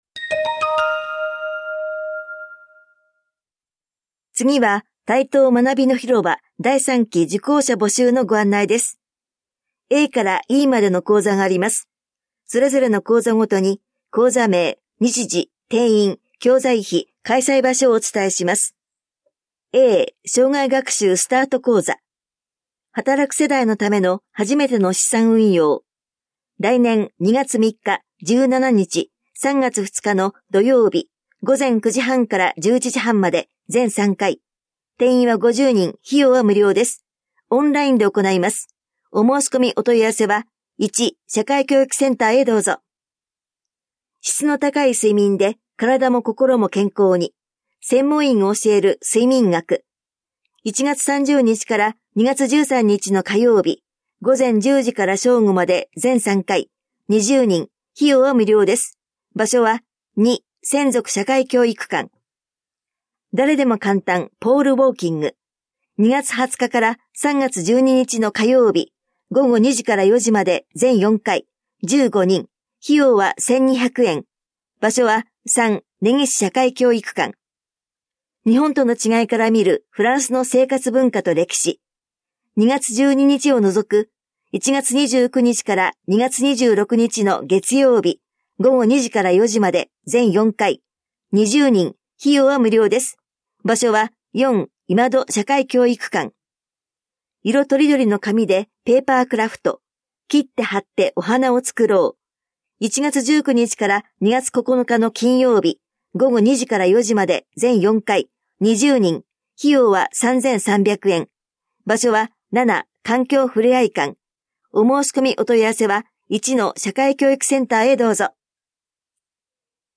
広報「たいとう」令和5年11月20日号の音声読み上げデータです。